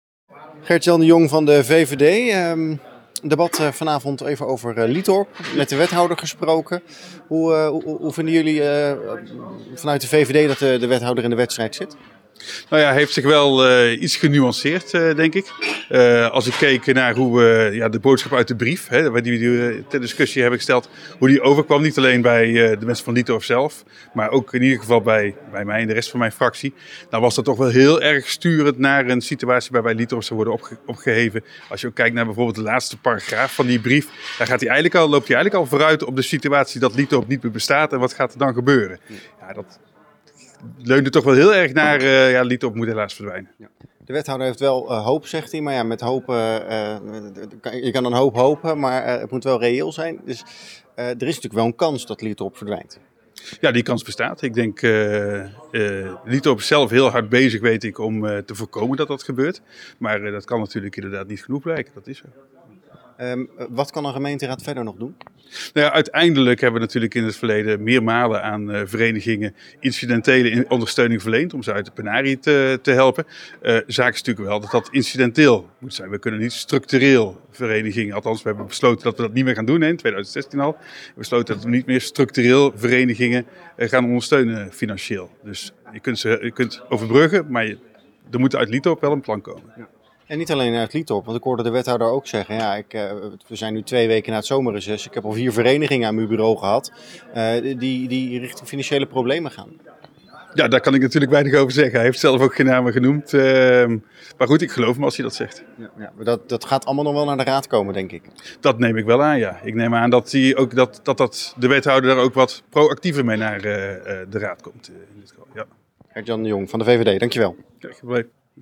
Raadslid van de VVD Gert-Jan de Jong over Liethorp.